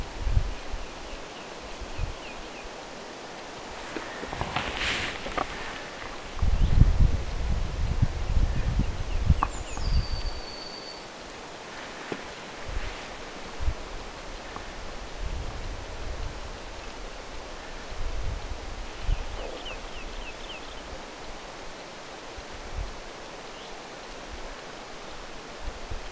punajalkaviklon ääntä
haarapääskysen pesäpaikan ympäristöstä
punajalkaviklo_jossain_rantaniitylla_ehka_erikoinen_paikka.mp3